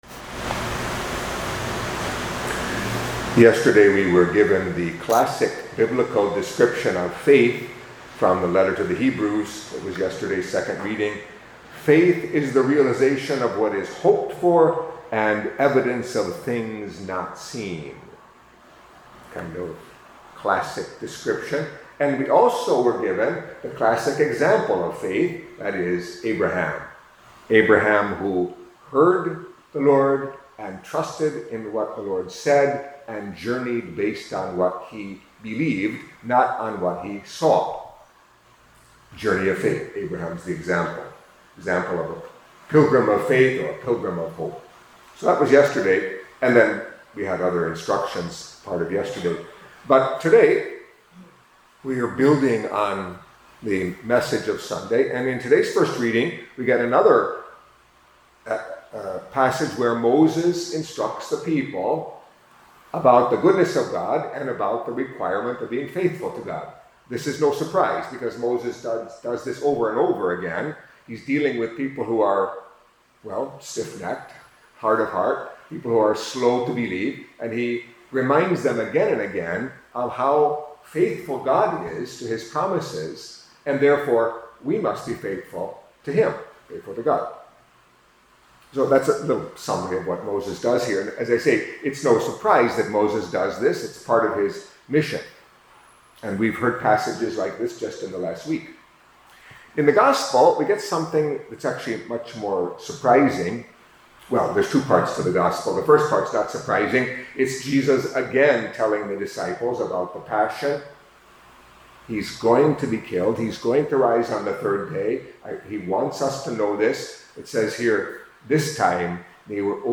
Catholic Mass homily for Monday of the Nineteenth Week in Ordinary Time